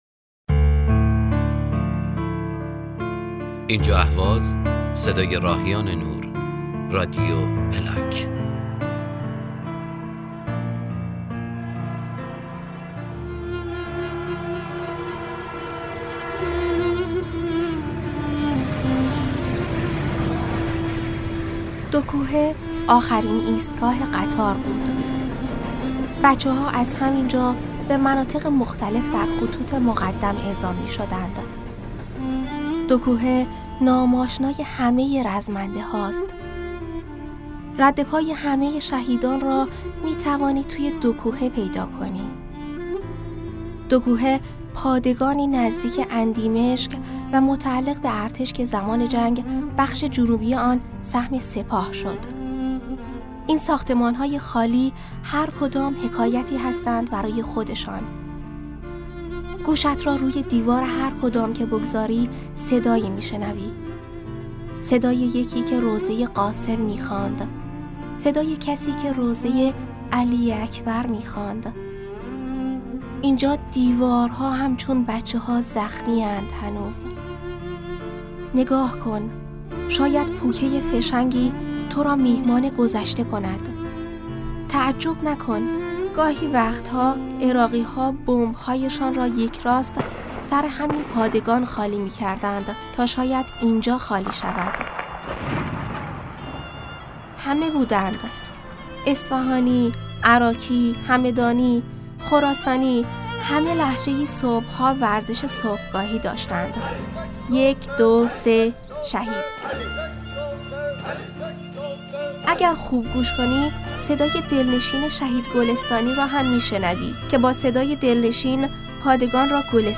روایتی شنیدنی و جذاب از پادگان دوکوهه